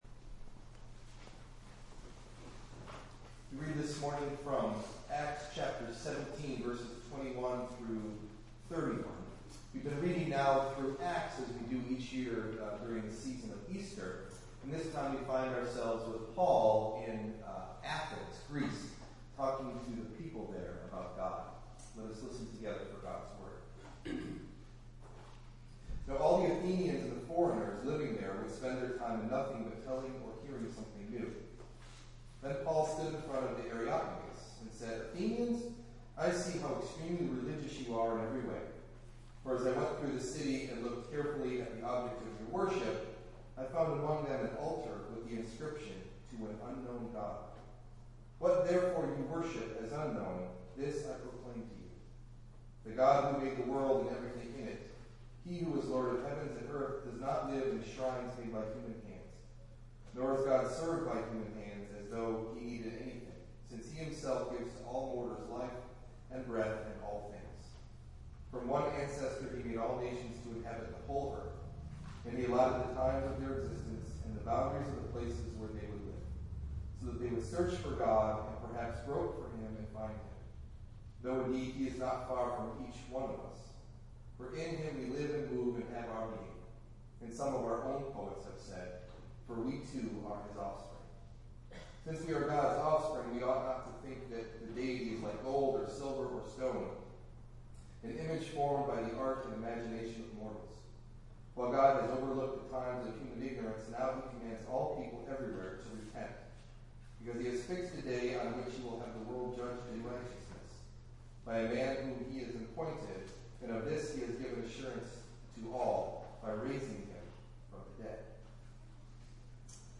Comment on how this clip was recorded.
Delivered at: The United Church of Underhill